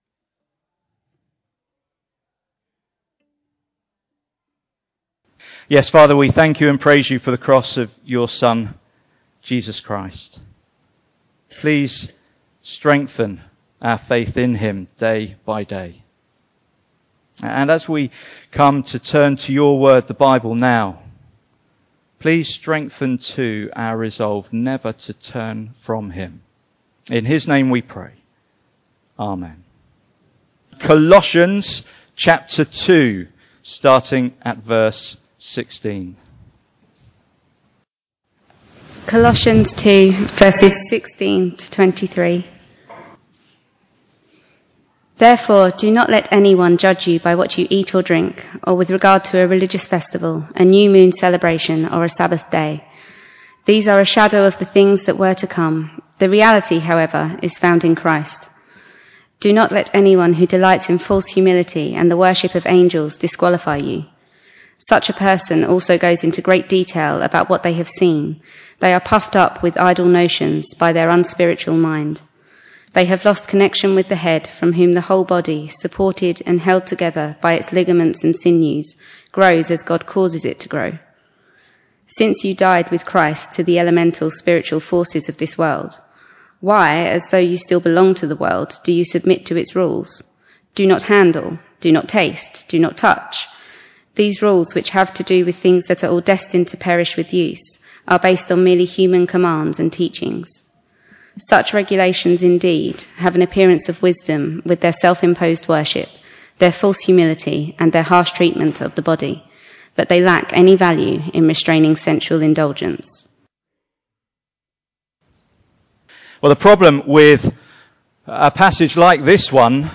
Passage: Colossians 2:16-23 Service Type: Sunday Morning